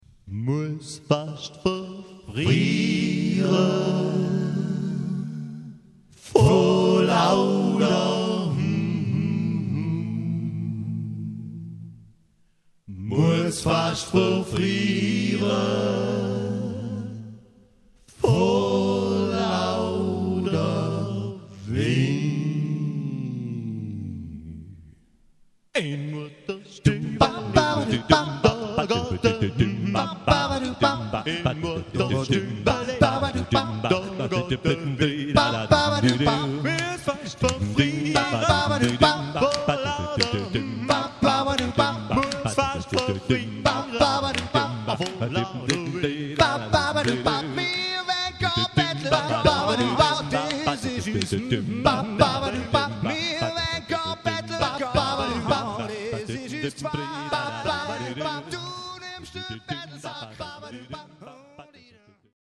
A-Cappella
live & authentisch.
Ohne Playback, ganz ohne Schnickschnack – aber mit jeder Menge Herz und Leidenschaft.